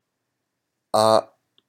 japanese_a_vowel.m4a